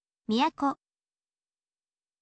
miyako